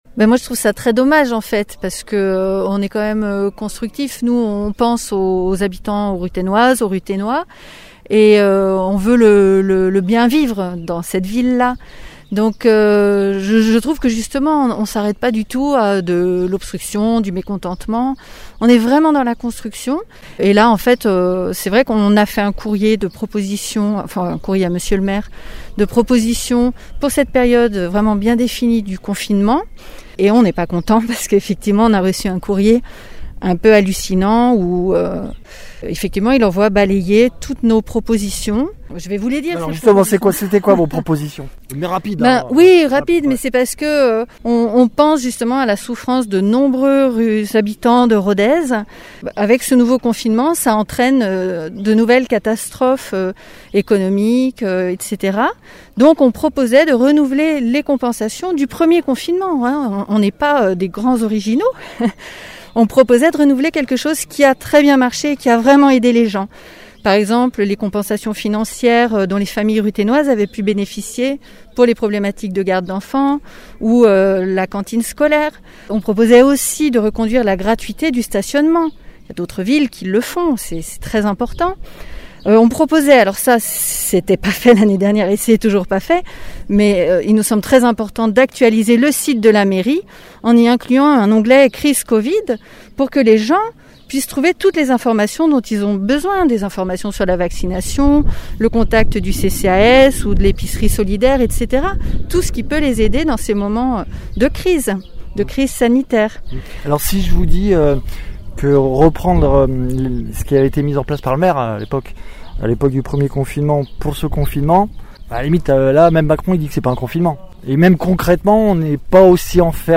Interviews
Invité(s) : Eléonor Echène, conseillère municipale d’opposition ’’Rodez citoyen’’